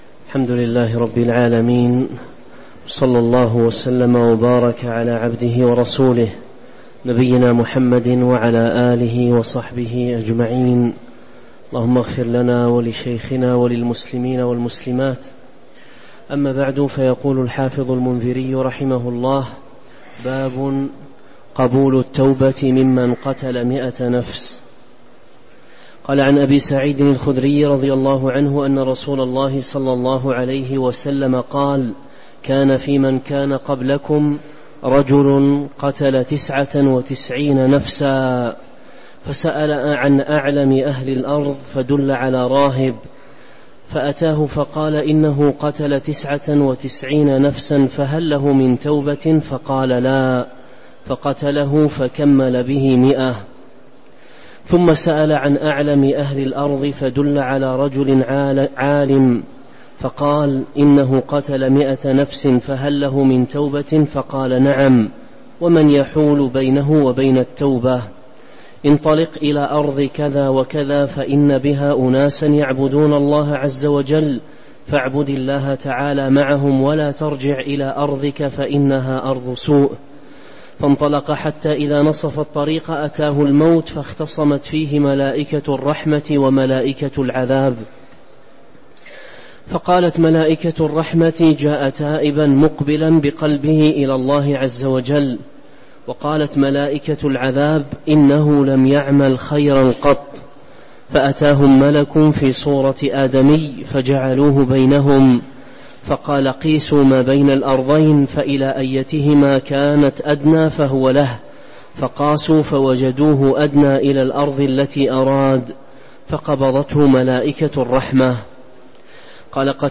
تاريخ النشر ١٦ ذو الحجة ١٤٤٣ هـ المكان: المسجد النبوي الشيخ